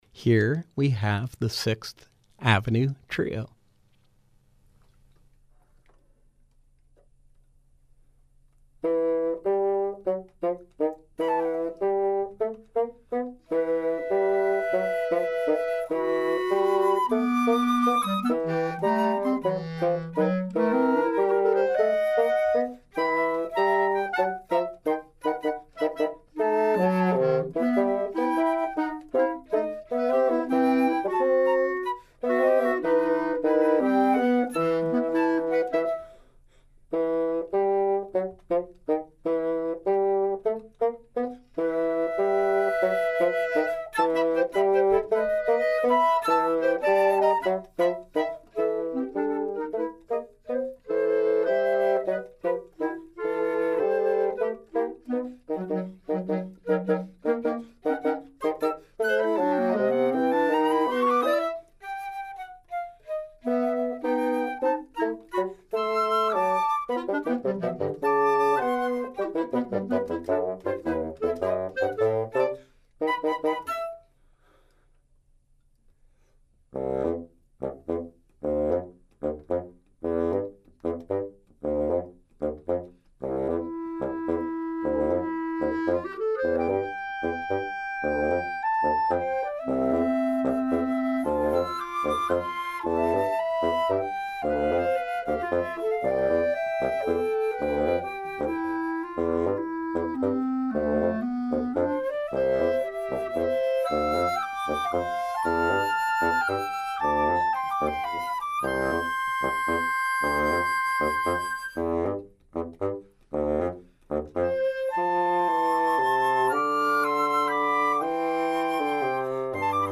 flute
clarinet
bassoon